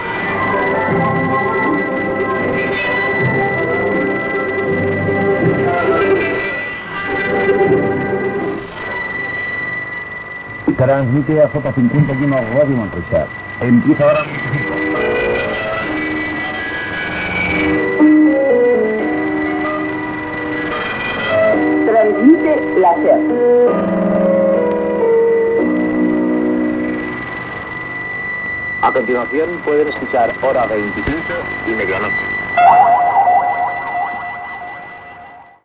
8afd6121fc9f43c7e6d3ede399ae69115e23c8b4.mp3 Títol Ràdio Manresa Emissora Ràdio Manresa Cadena SER Titularitat Privada local Descripció Identificació, indicatiu de la cadena SER, propers programes.
Qualitat de l'àudio deficient